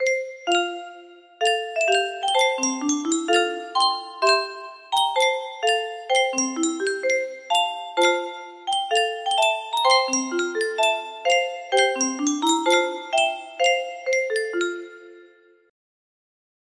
Yunsheng Music Box - Unknown Tune 2379 music box melody
Full range 60